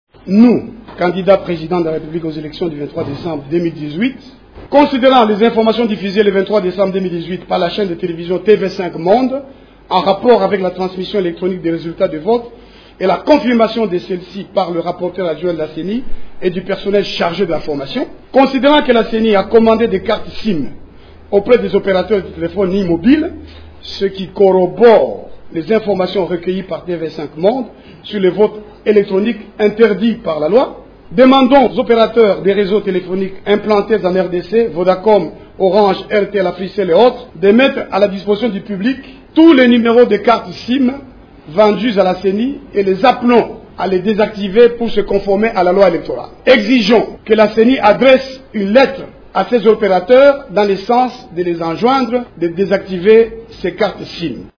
l’a déclaré mardi 25 décembre à Kinshasa au cours d’un point de presse.